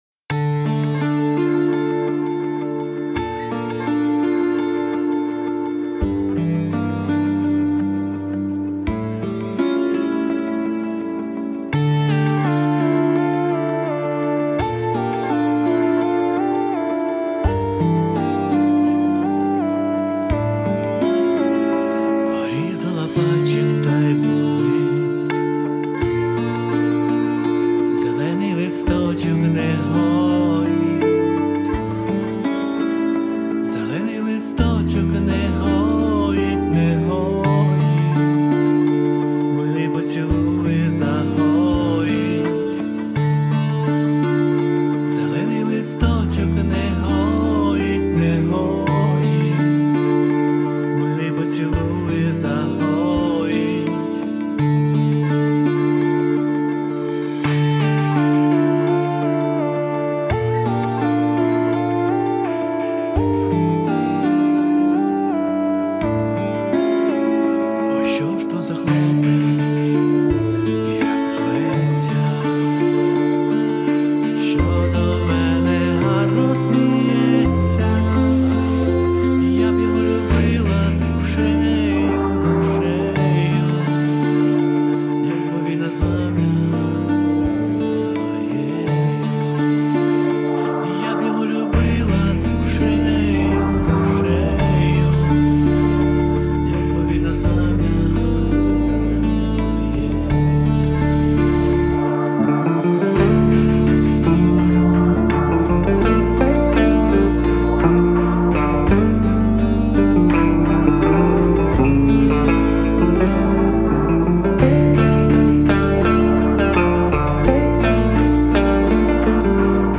Всі мінусовки жанру Pop-UA
Плюсовий запис